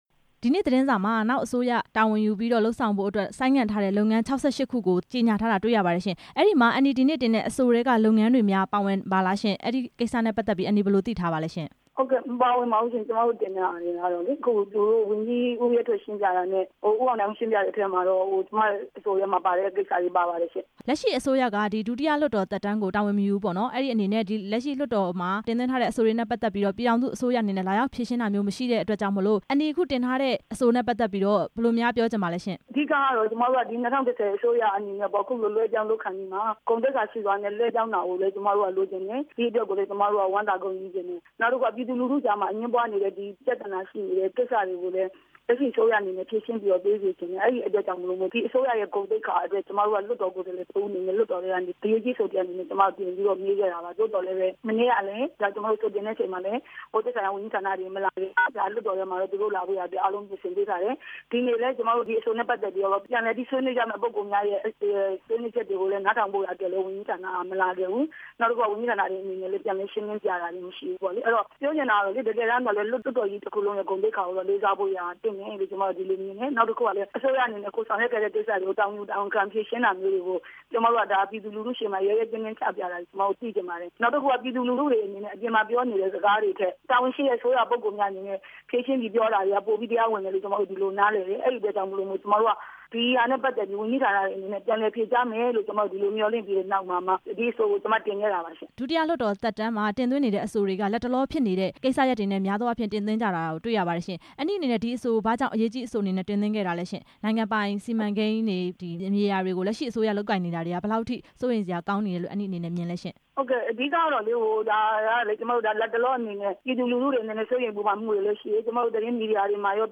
ပုလဲမြို့နယ် ပြည်သူ့လွှတ်တော်ကိုယ်စားလှယ် ဒေါ်ခင်စန်းလှိုင်နဲ့ မေးမြန်းချက်